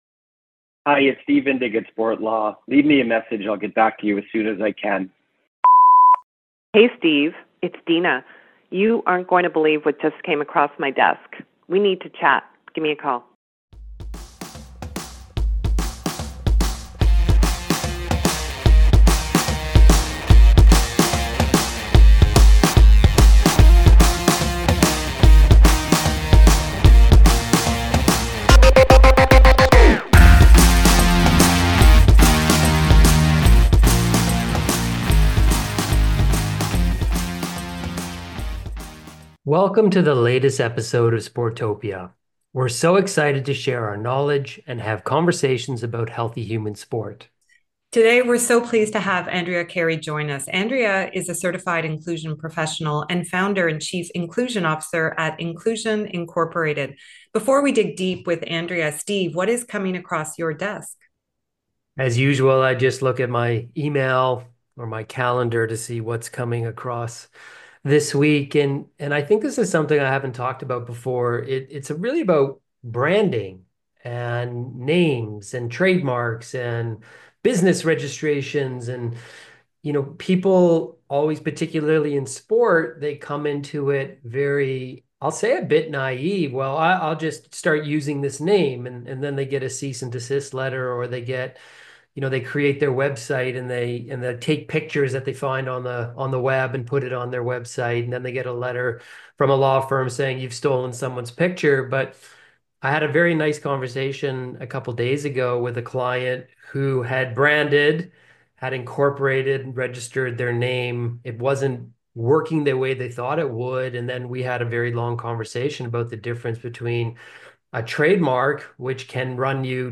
The team at Sport Law is committed to uplifting the sector, and we want to do our part by offering candid conversations on the current state of sport and share our insights to better address issues related to governance, safe sport, leadership challenges, trends in human relations and so much more.